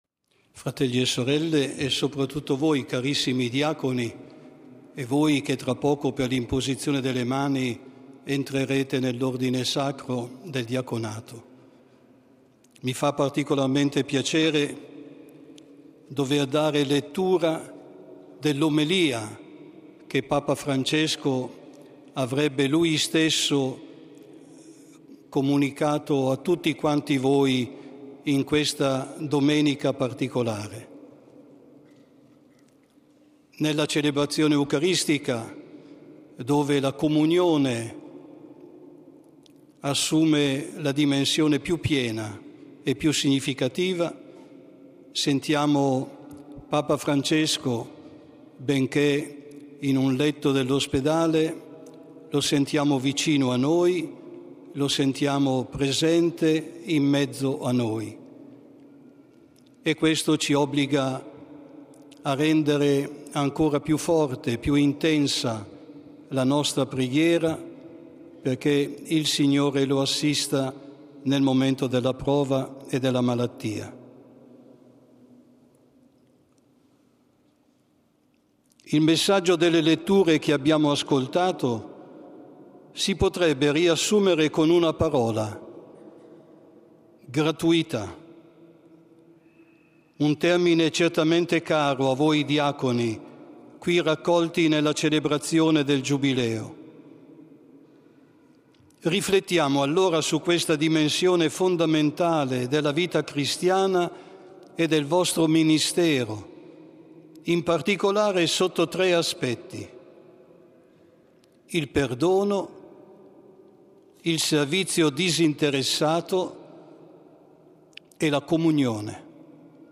This podcast offers the public speeches of the Holy Father, in their original languages.